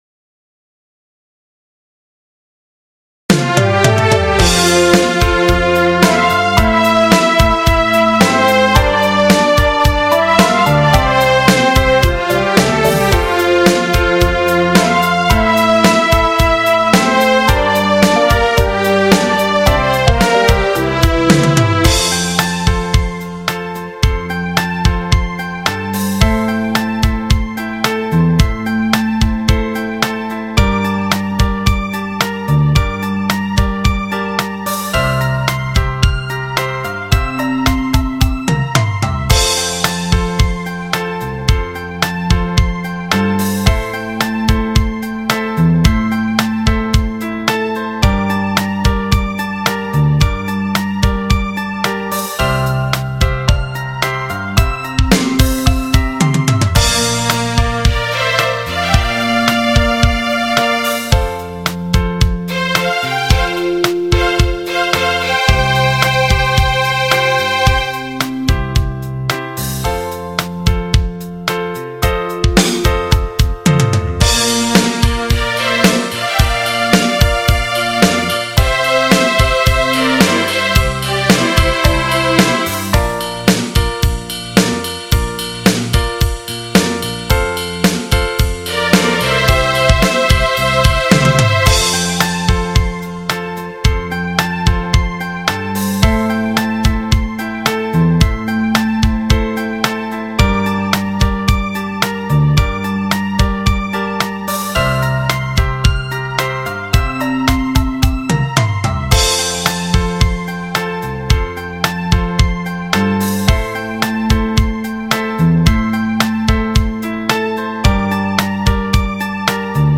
BASE MIDY KARAOKE
CON  SOLO E  FINALE VOCE  °°°